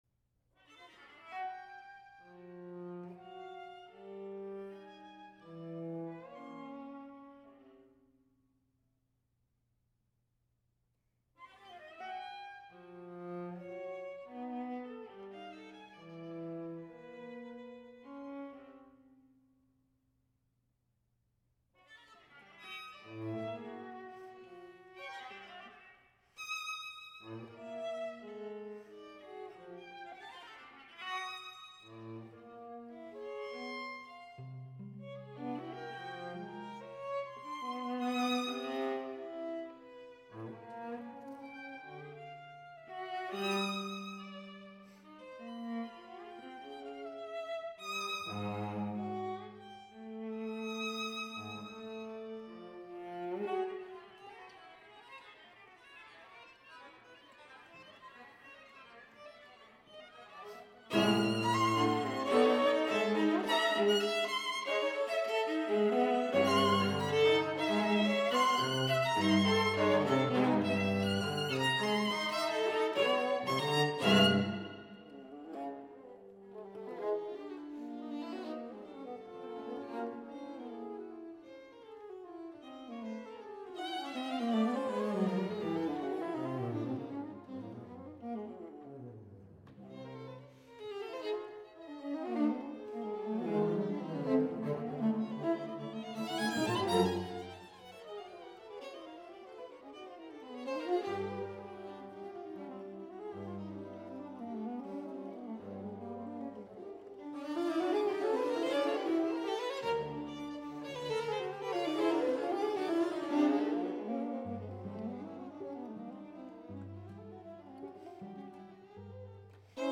TEMPO GIUSTO (333)